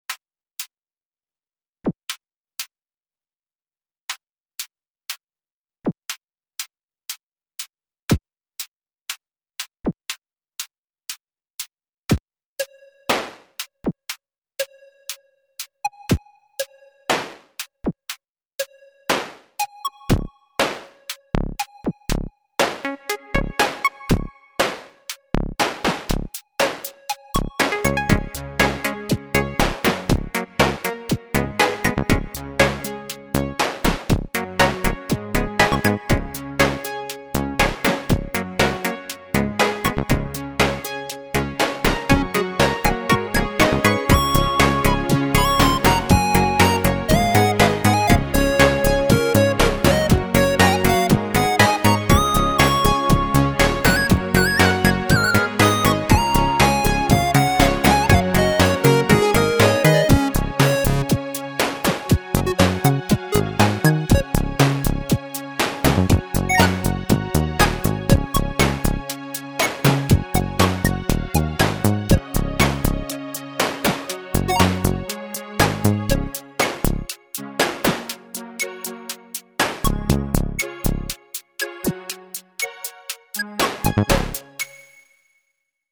Det mjuka stråkljudet och melodin + SID-liknande effekter är spelat 100% live. Resten är med sequencer. (En för varje trumma, bas och synt i detta fallet.)
För att göra det tydligare så byggde jag låten så den växer steg för steg, det är därför det är så tomt i början.
Trummorna och några synthljud kommer från hemmabyggda prylar.